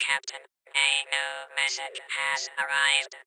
RadioNewMessage.ogg